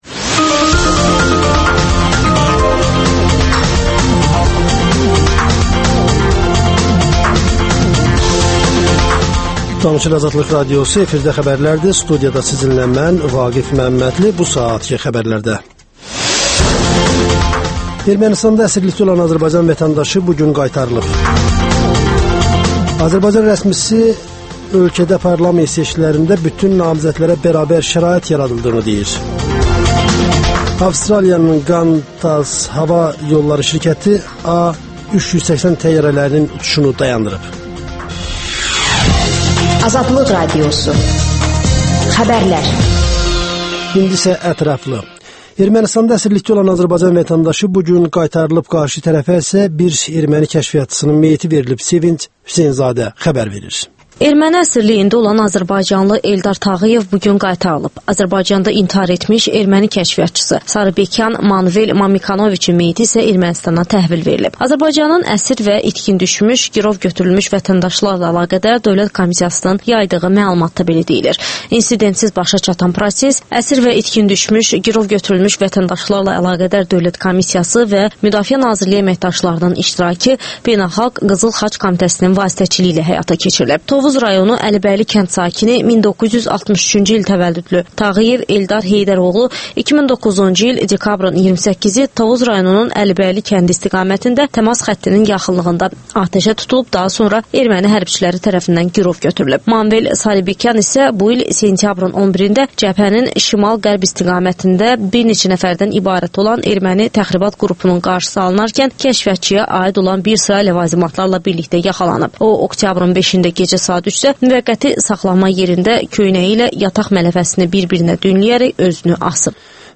İşdən sonra - Yazıçı Əkrəm Əylisli ilə söhbət